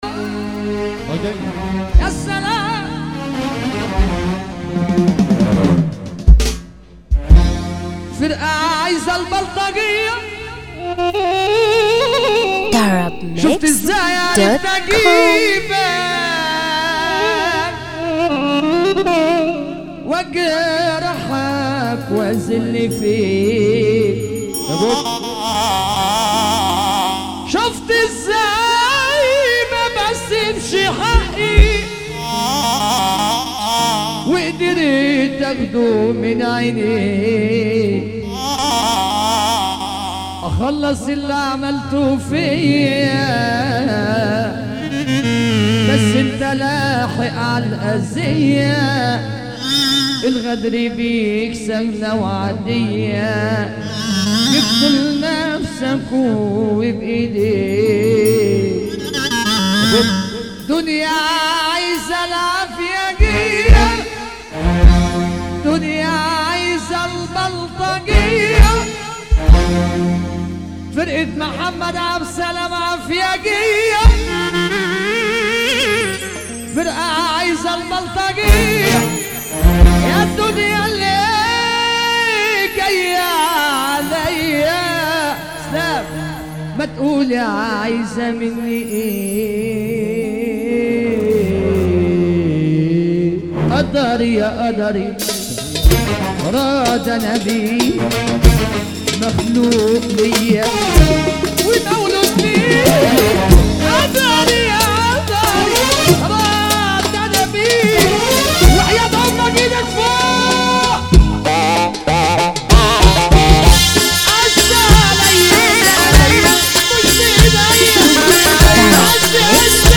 موال
بشكل حزين